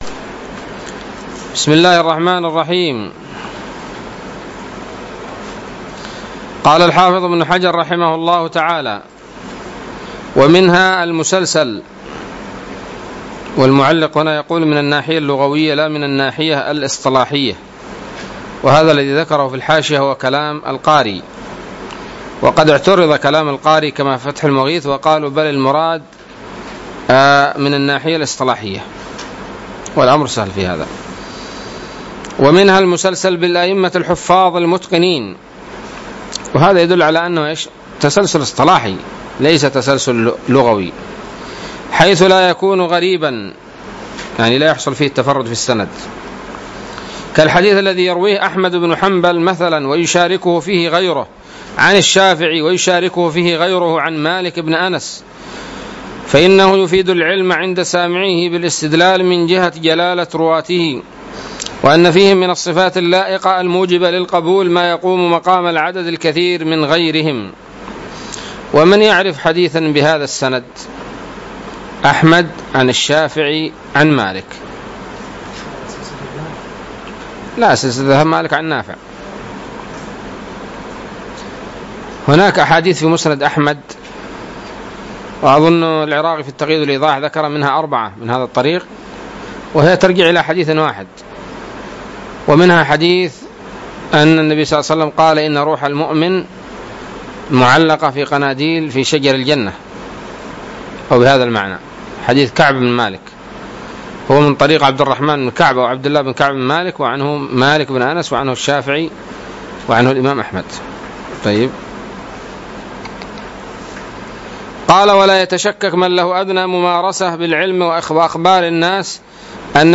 الدرس الثامن من شرح نزهة النظر